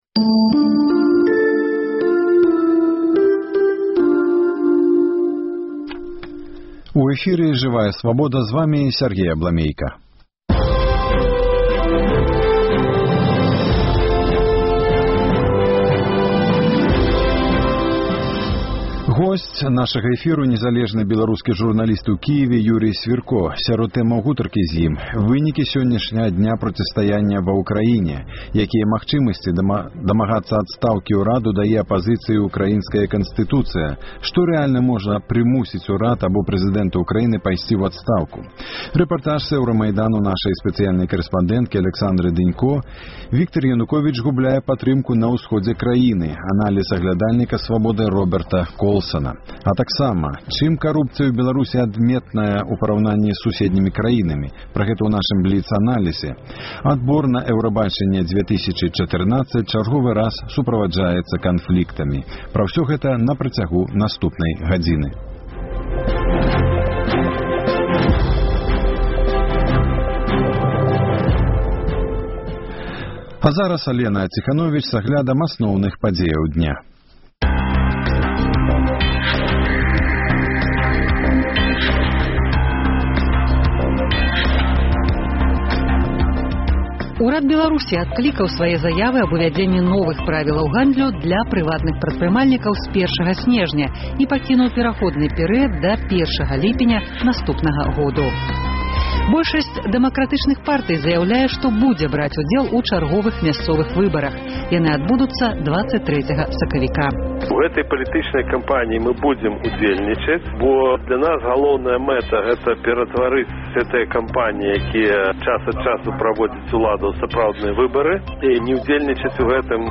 Апытаньне ў Гомелі. Як падзеі ва Ўкраіне могуць адбіцца на Беларусі?